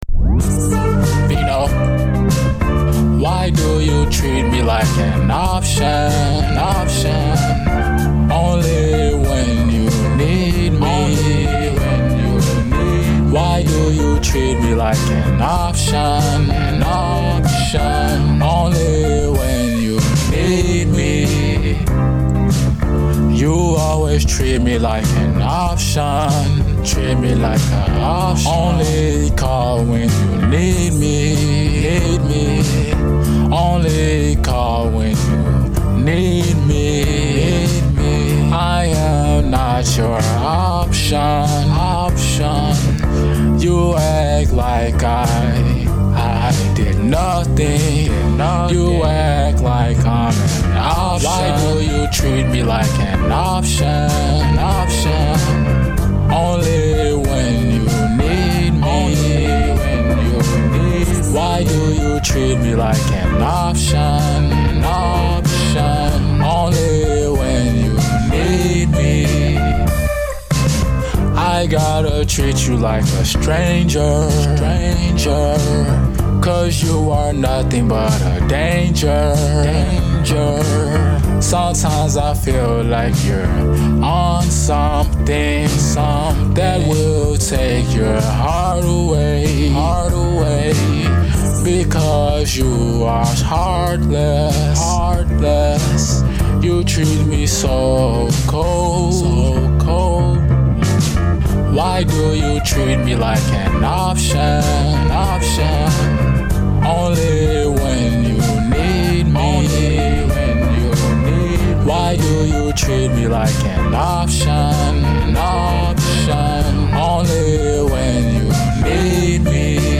Bed Room Pop
Sad And Guitar Like Vibes